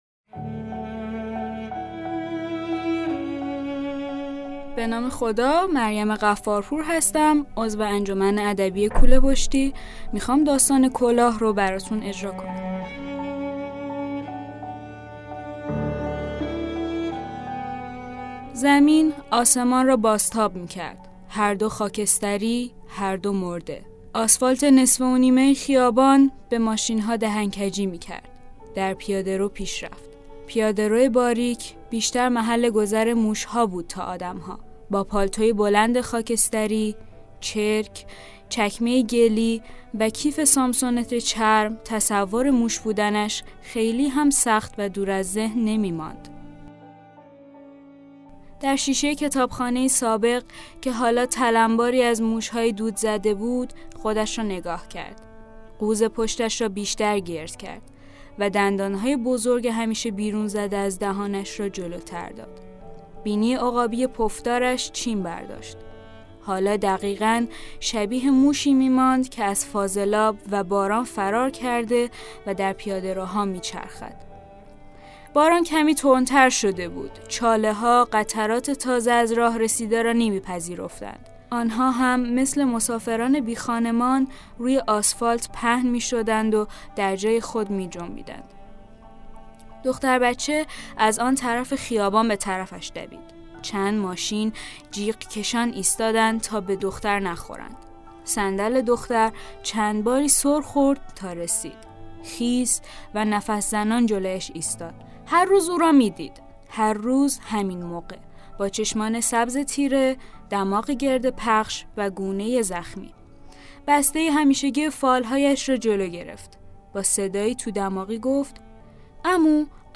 کودک / چند رسانه‌ای
برچسب ها: داستان پادکست پادکست داستان قصه